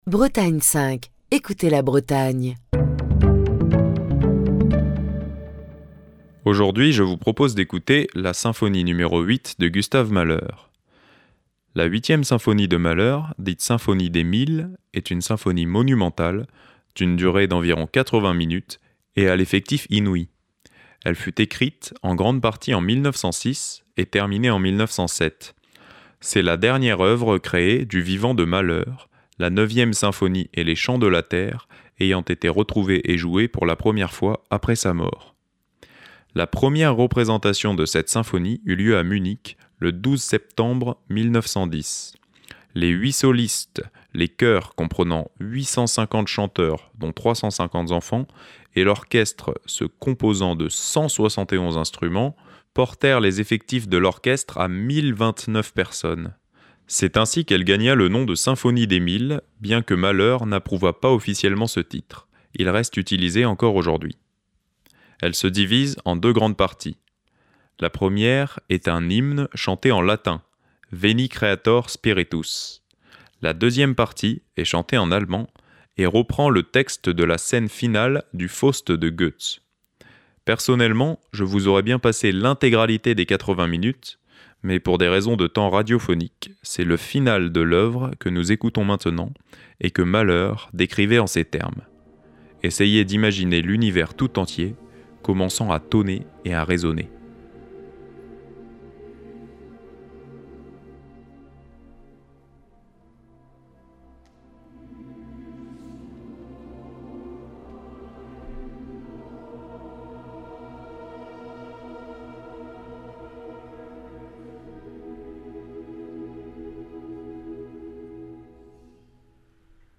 Elle se divise en deux grandes parties : La première est un hymne chanté en latin : "Veni Creator Spiritus". La deuxième partie est chantée en allemand et reprend le texte de la scène finale du Faust de Goethe.
» Voici donc le final tonitruant de la 8e symphonie de Gustav Mahler, interprété par le Philharmonia sous la direction de Giuseppe Sinopoli.